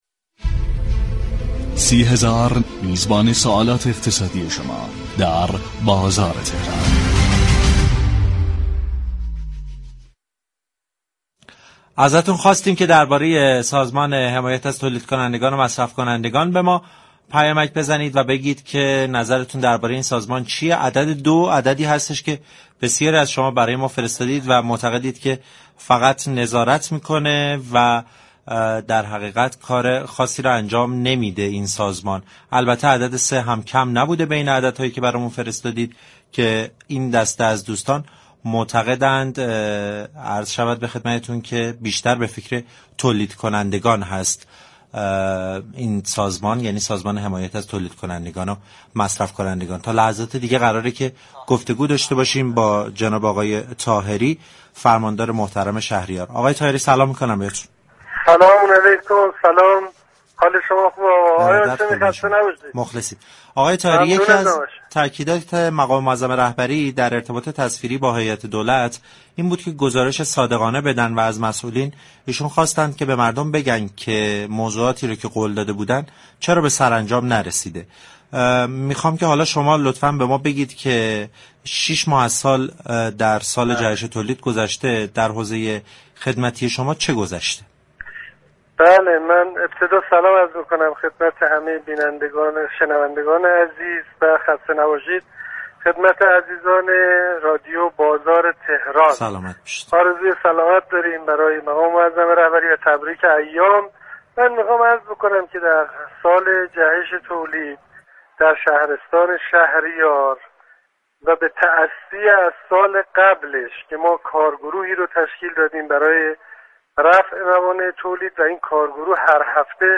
نورالله طاهری، فرماندار شهریار درباره اقدامات صورت گرفته در این شهرستان در راستای جهش تولید و اتكاء به تولیدات داخلی با بازار تهران گفتگو كرد.